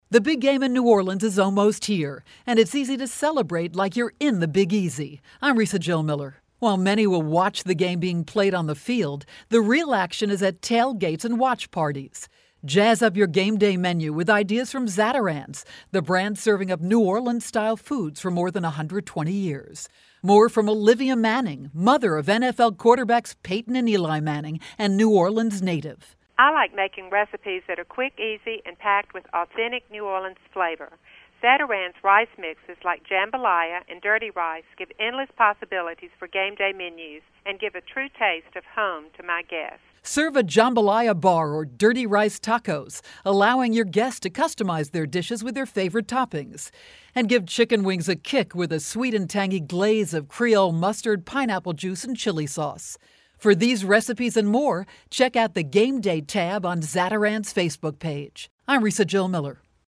January 31, 2013Posted in: Audio News Release